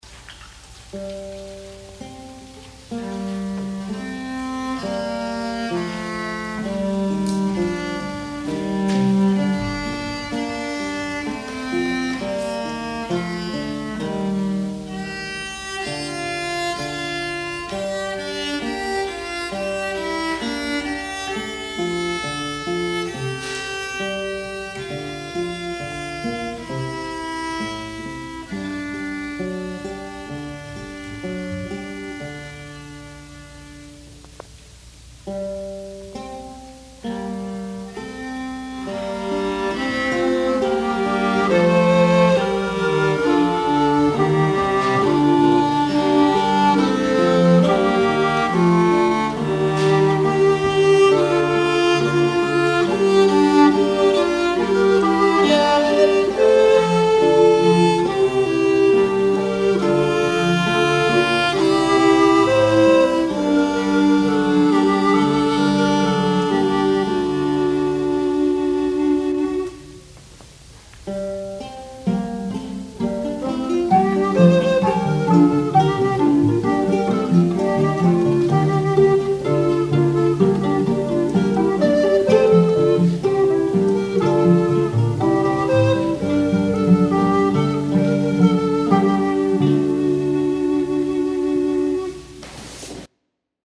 | Instrumental Ensemble 'Ease After Warre' 1982